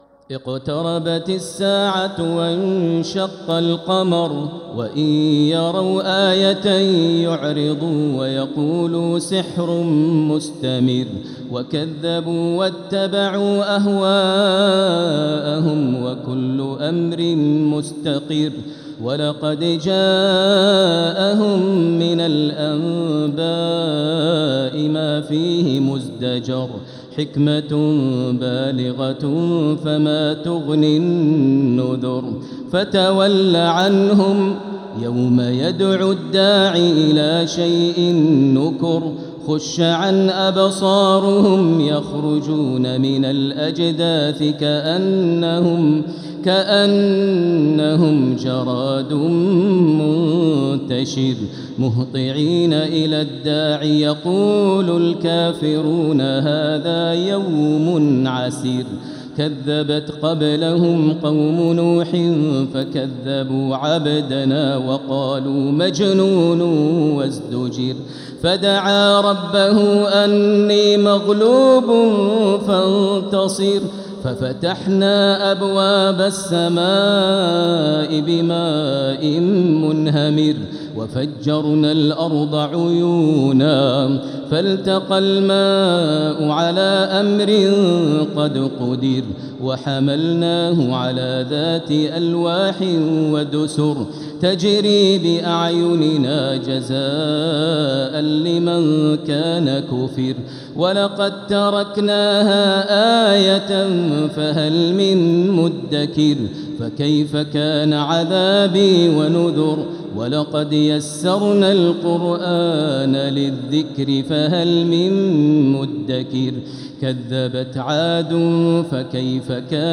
سورة القمر | مصحف تراويح الحرم المكي عام 1446هـ > مصحف تراويح الحرم المكي عام 1446هـ > المصحف - تلاوات الحرمين